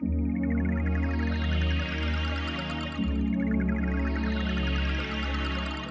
01F-PAD-.A-R.wav